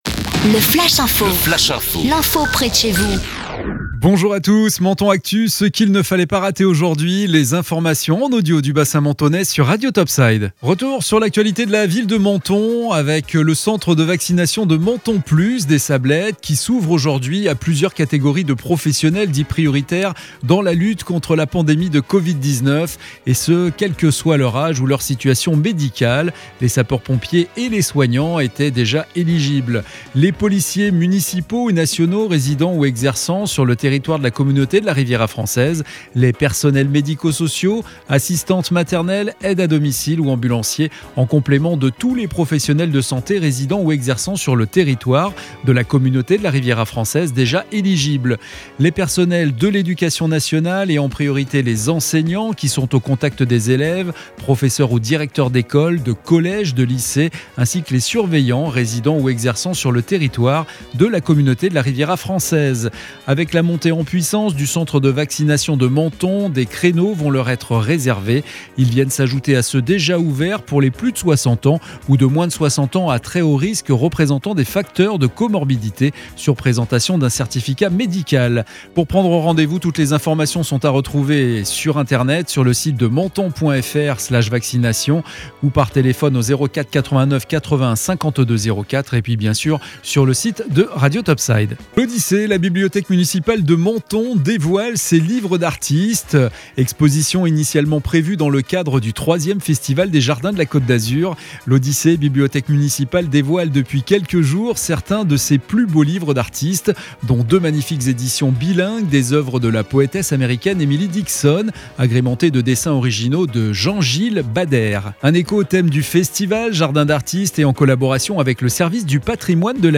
Menton Actu - Le flash info du vendredi 16 avril 2021